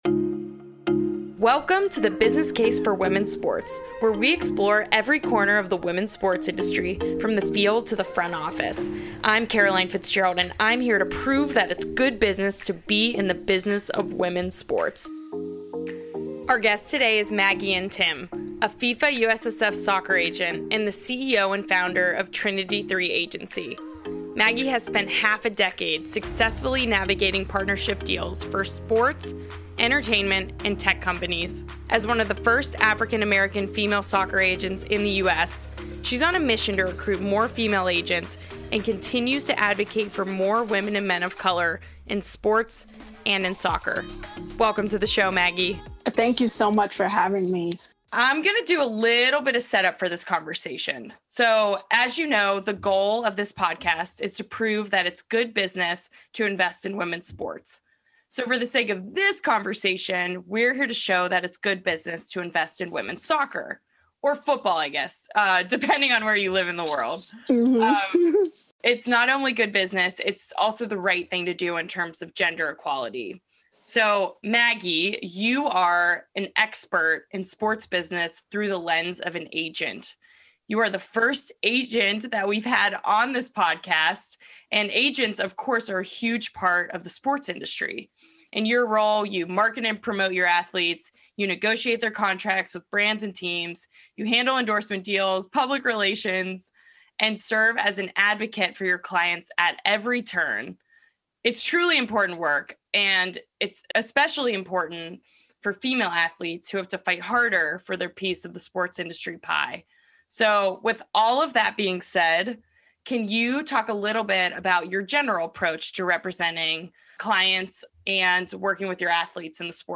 Ep. #109 A Conversation About Women, Money & Sports, ft.